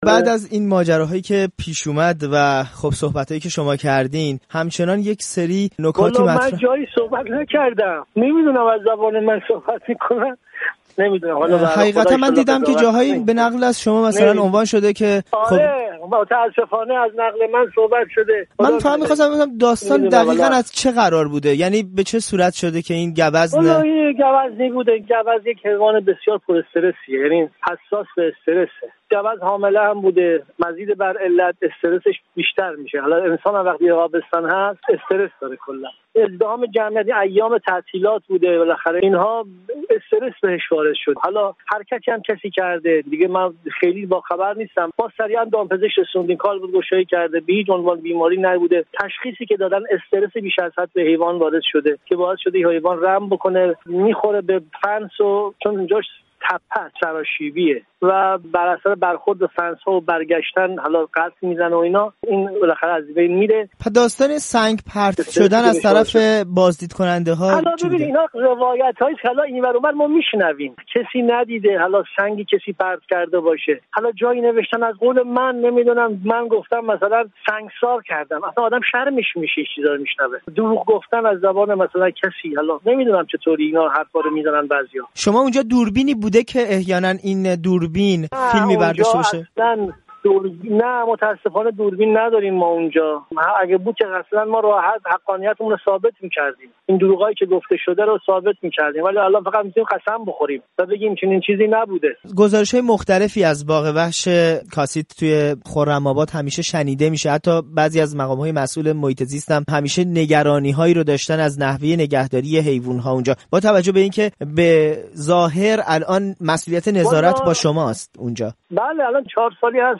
بیست و پنجم فروردین ماه خبرگزاری فارس خبری را مبنی بر «سنگسار» یک گوزن زرد ایرانی با جنینی نارس در شکم، در استان لرستان منتشر کرد. اما مدیرکل دامپزشکی استان لرستان در گفت و گو با رادیو فردا انتشار اخبار مربوط به «سنگسار» این گوزن را تکذیب می کند.